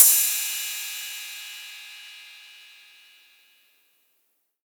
808CY_7_TapeSat_ST.wav